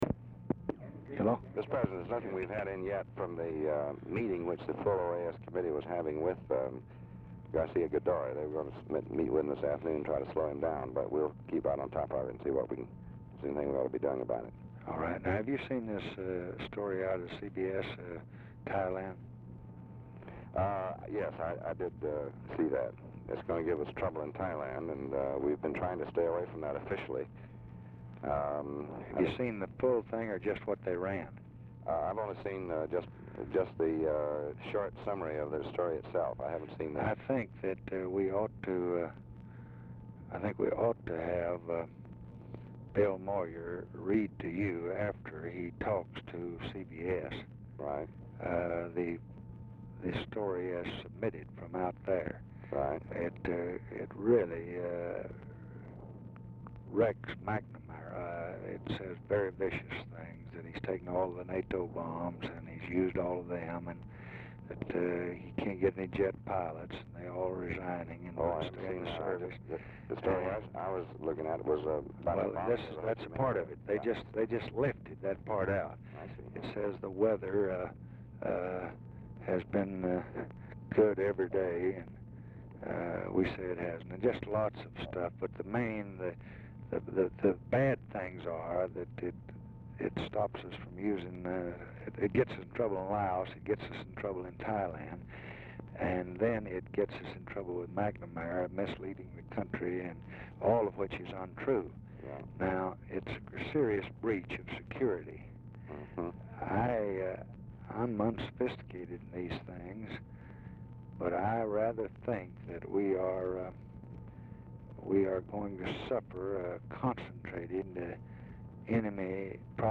Telephone conversation # 9446, sound recording, LBJ and DEAN RUSK, 1/6/1966, 5:02PM · Discover Production
Location of Speaker 1: Oval Office or unknown location
Format: Dictation belt